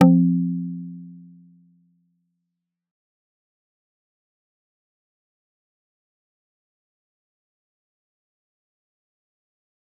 G_Kalimba-F3-f.wav